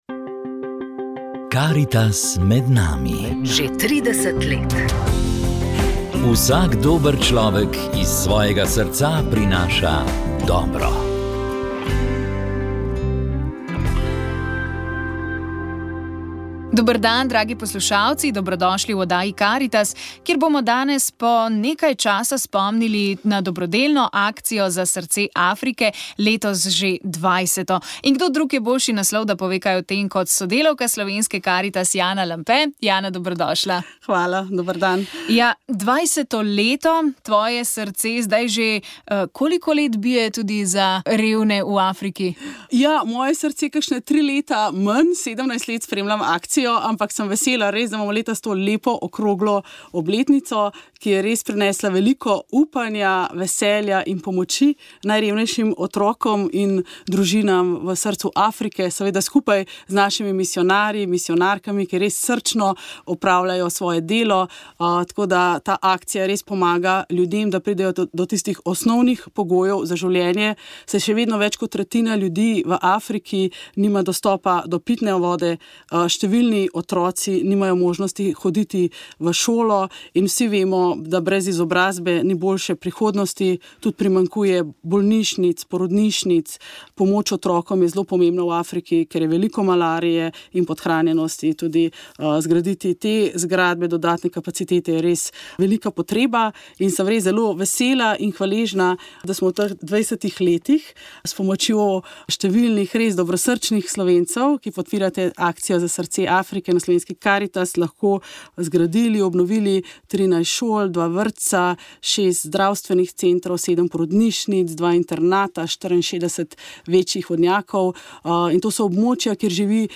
Ob sklepu Tedna Karitas smo se pogovarjali o pomenu druženja s starejšimi in osamljenimi. Spomnili pa smo se tudi uspešno izpeljanega koncerta Klic dobrote.